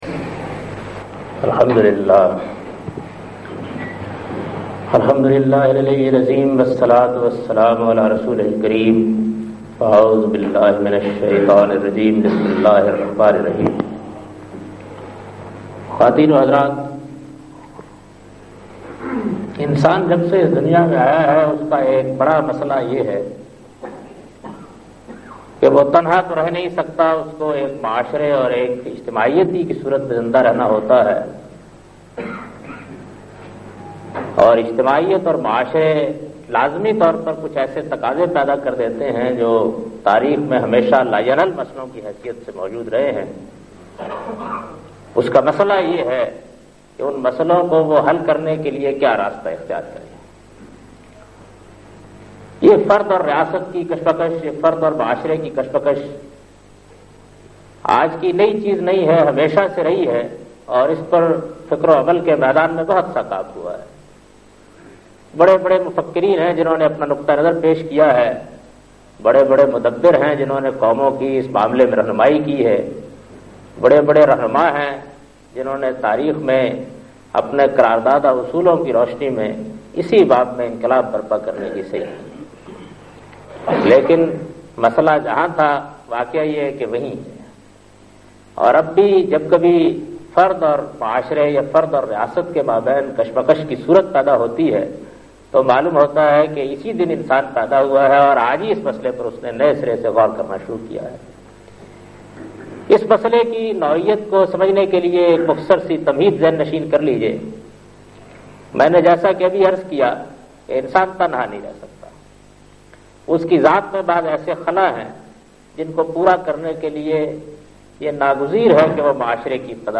Lecture by Javed Ahmad Ghamidi on the topic -Our Parliamentry system and Islam.